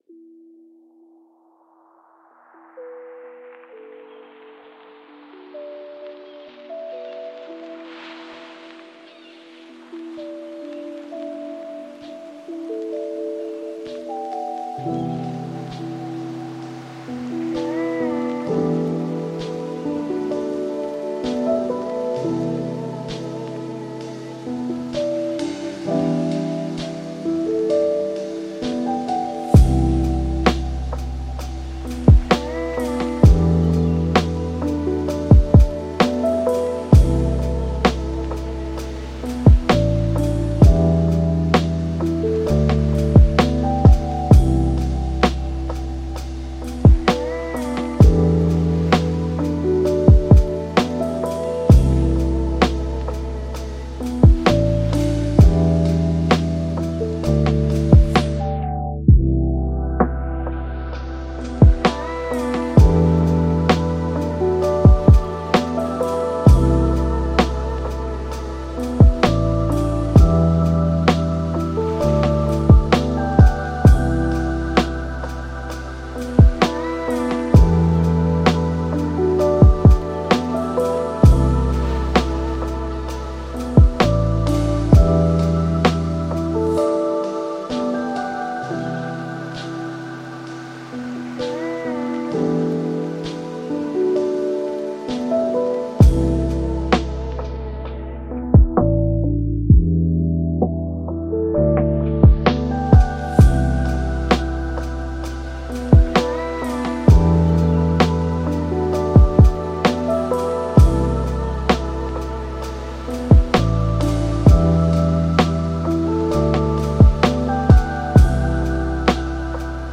Progressive Rock, Art Rock